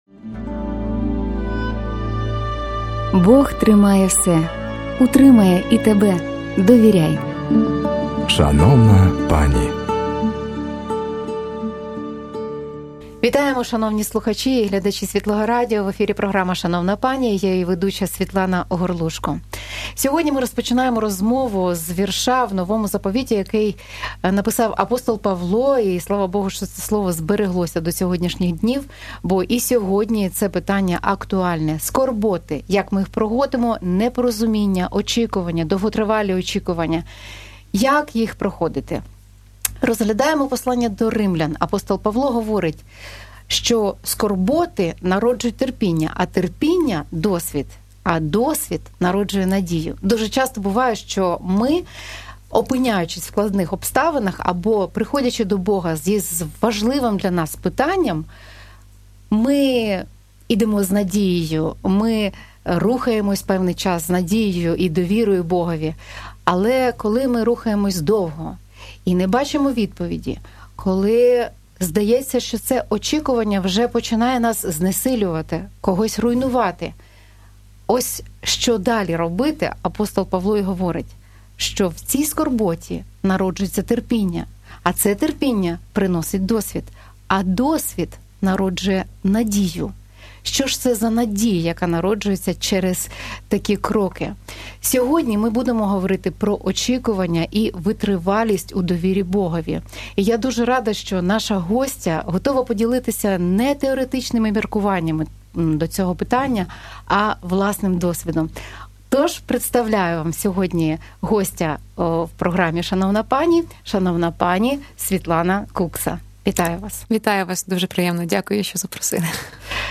Віримо, що ця розмова стане підтримкою для...